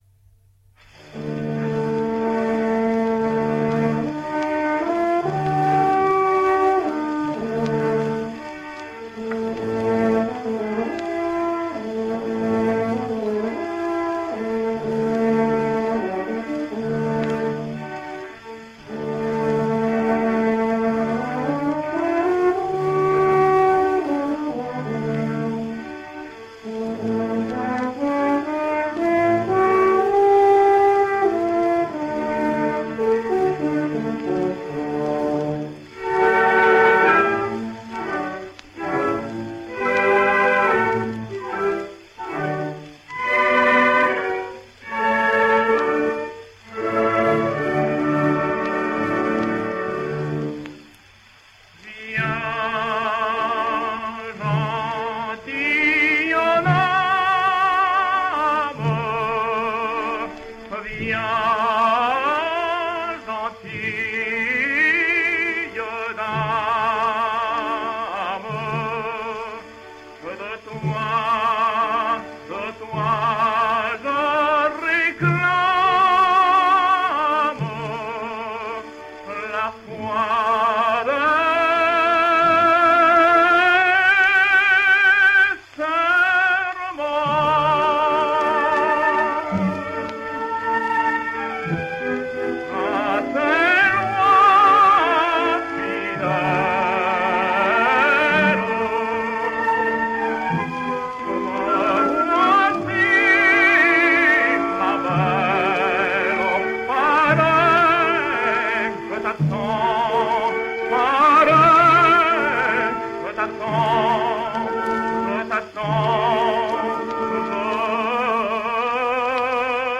Spanish Tenor.
He recorded for Pathé and Odeon and I have chosen a Pathé that testing George Brown aria from La Dame Blanche which received a virtuoso performance here.